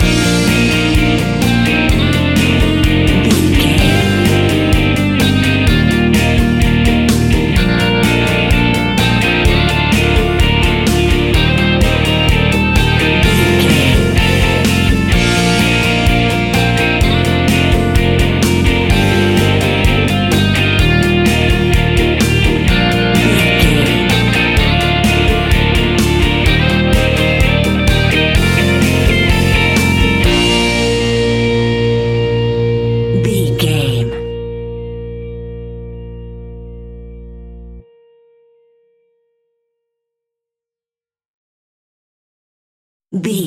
Ionian/Major
B♭
indie pop
energetic
uplifting
cheesy
guitars
bass
drums
piano
organ